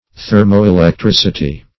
Thermoelectricity \Ther`mo*e`lec*tric"i*ty\, n. [Thermo- +